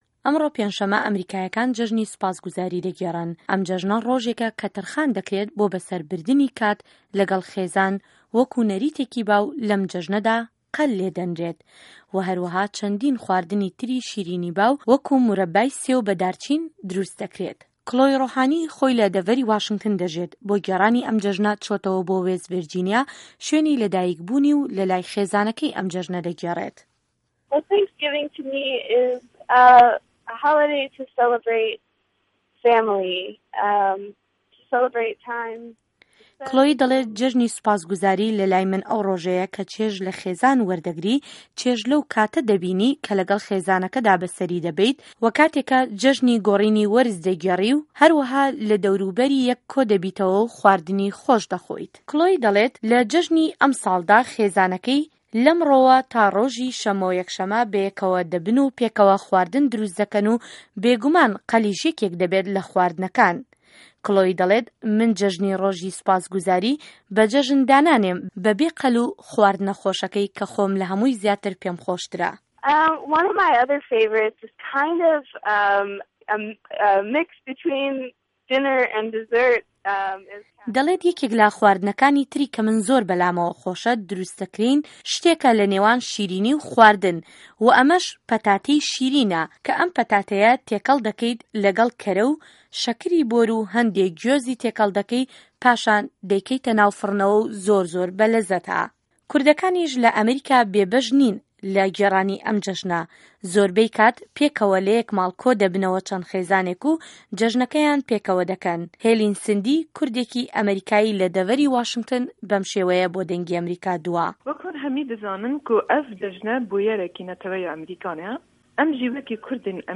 ڕاپۆرتی تایبه‌ت